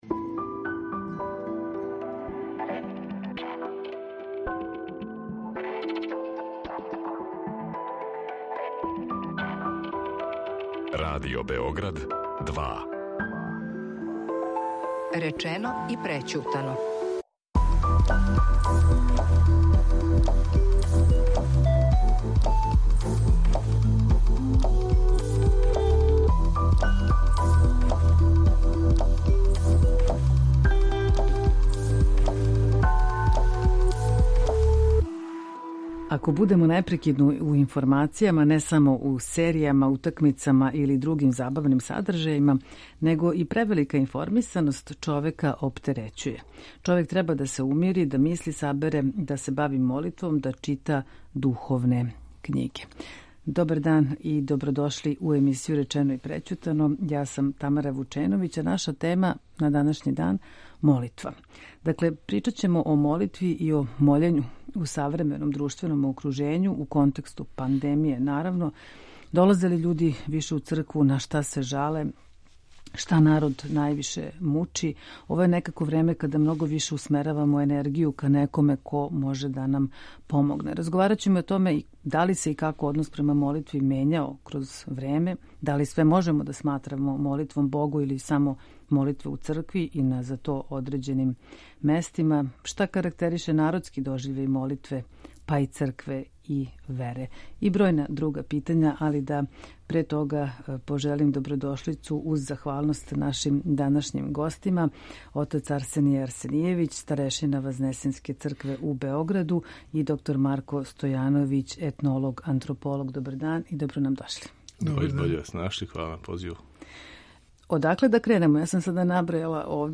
У данашњој емисији Речено и прећутано разговарамо о молитви у савременом друштвеном окружењу.